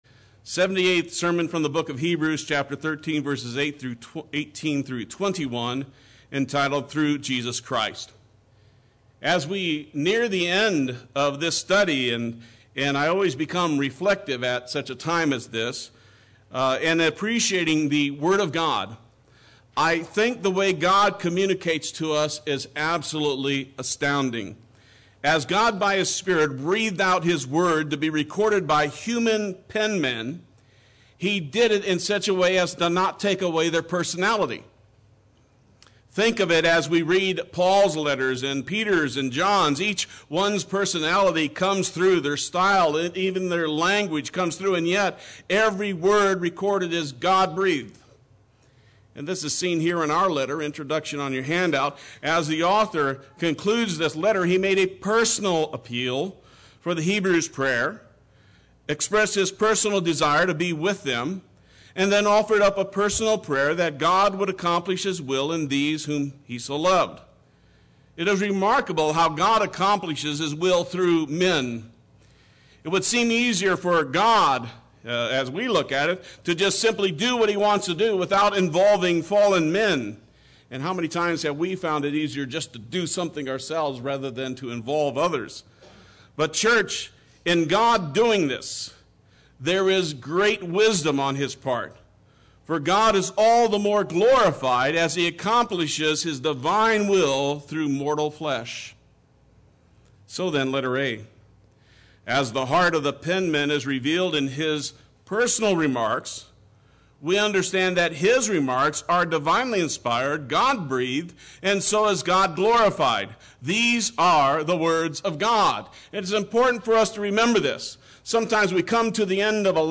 Play Sermon Get HCF Teaching Automatically.
Through Jesus Christ Sunday Worship